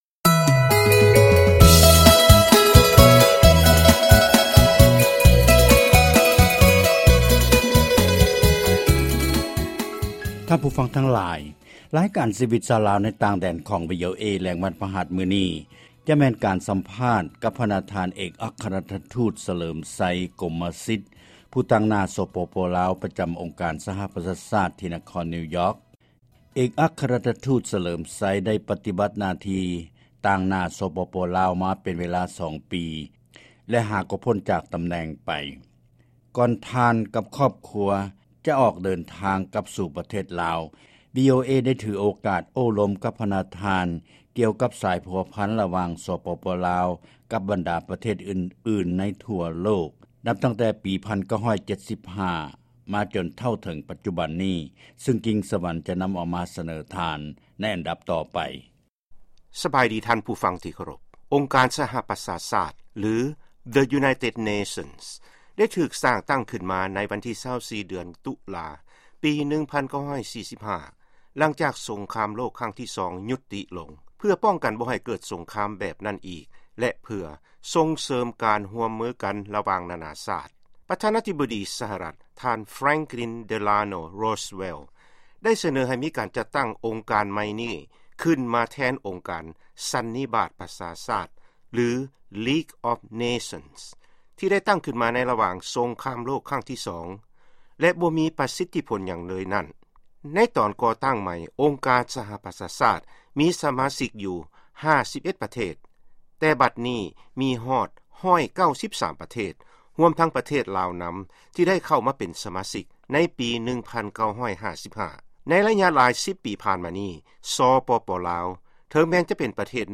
ຟັງການສໍາພາດ ພະນະທ່ານ ເອກອັກຄະລັດຖະມູດ ສະເຫລີມໄຊ ກົມມະສິດ